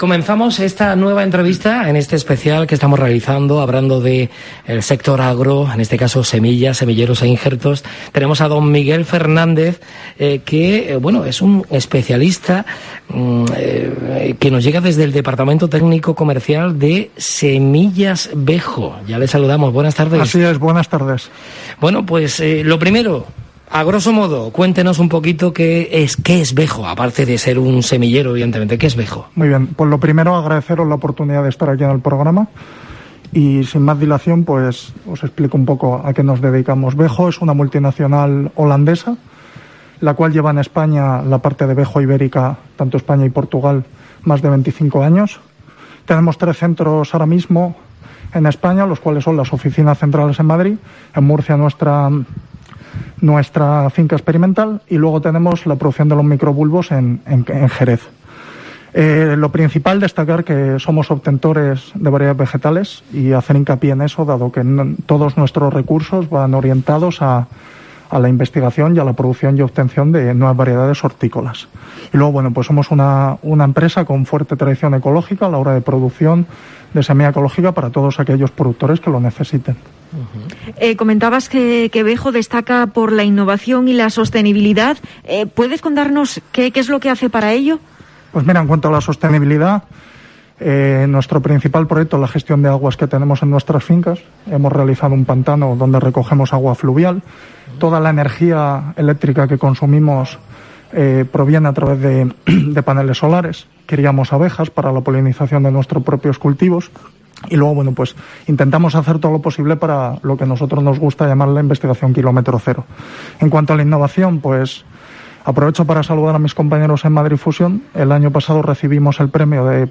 Bejo ha abierto el tramo de Mediodía COPE Almería dedicado, en esta segunda jornada, a las 'Semillas, semilleros e injertos', que COPE Almería está desarrollando, desde este lunes al viernes, en las instalaciones de Viagro.